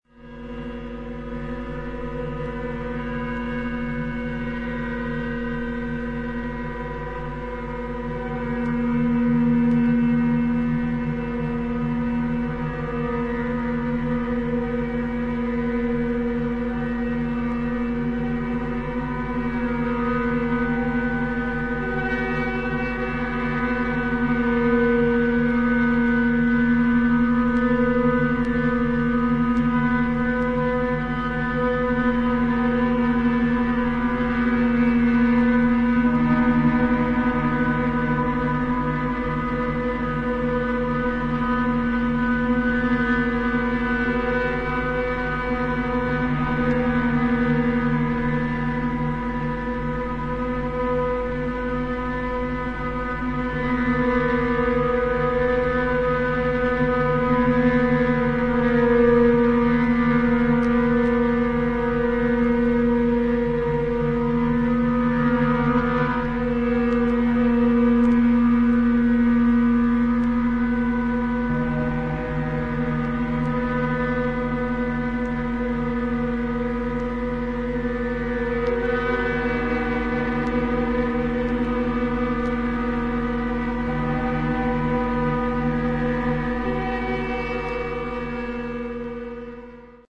アンビエント感のあるドローン・サウンドが幾重にもレイヤーされ、深みのある重厚な世界観を披露している素晴らしい内容。